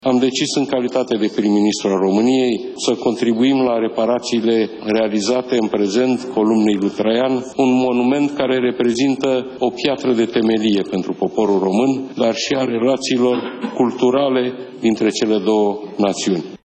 Premierul s-a aflat ieri, la Roma, la 13 ani de la ultima reuniune a guvernelor Italiei și României.